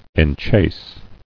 [en·chase]